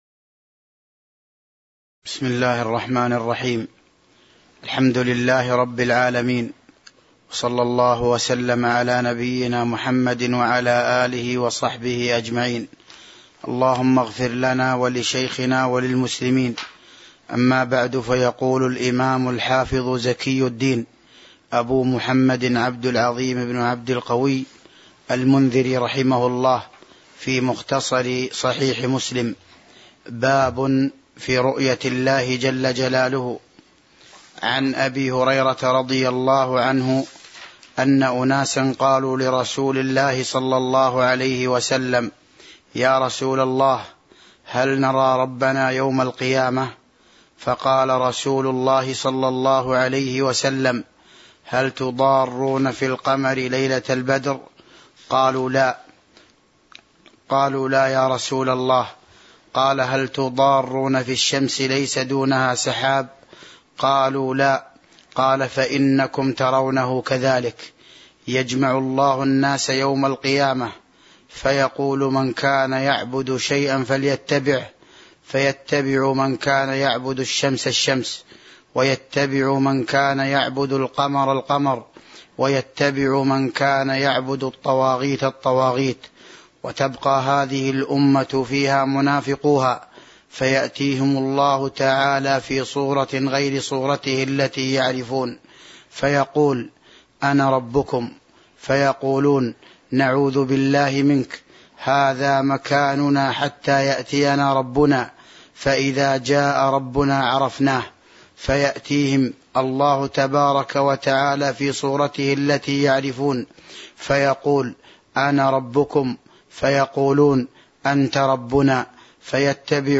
تاريخ النشر ١٦ ربيع الأول ١٤٤٢ هـ المكان: المسجد النبوي الشيخ: فضيلة الشيخ عبد الرزاق بن عبد المحسن البدر فضيلة الشيخ عبد الرزاق بن عبد المحسن البدر باب في رؤية الله جلّ جلاله (29)